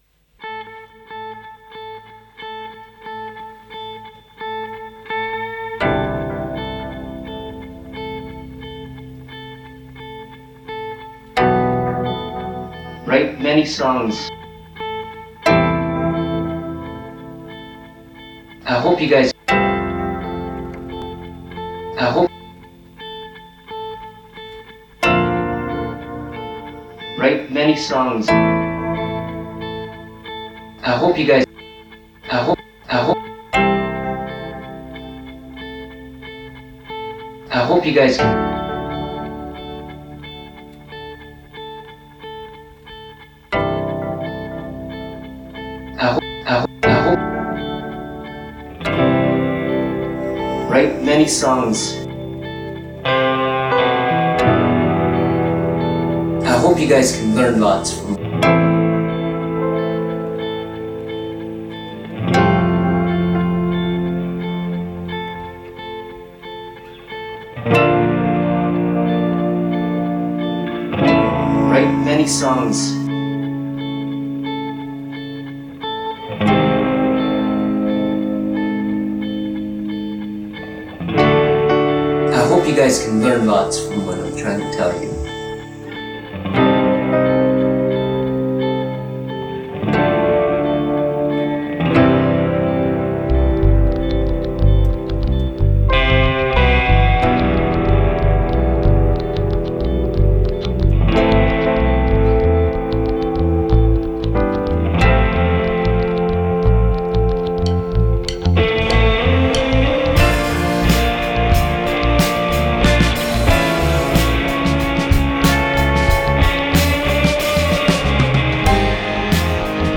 broadcast live and recorded
recorded live on August 17, 2022.